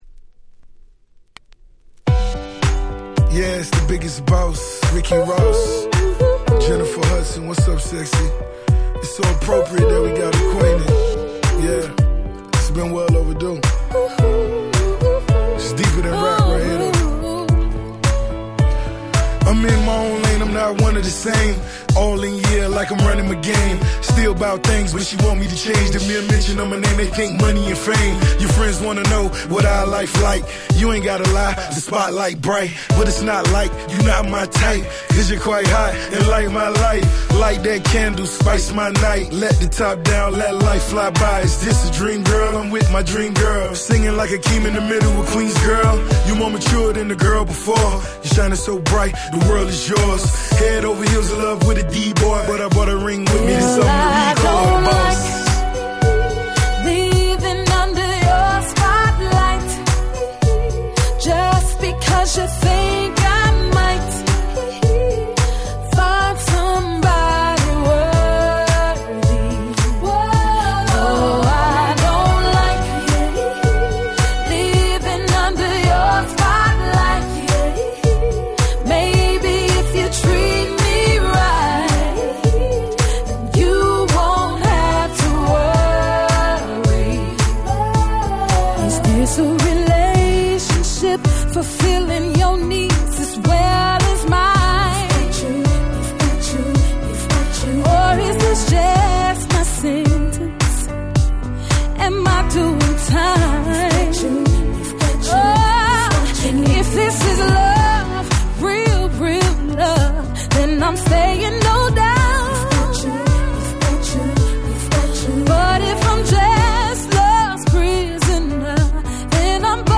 08' Super Hit R&B !!
GroovyでMoodyな素敵なR&B !!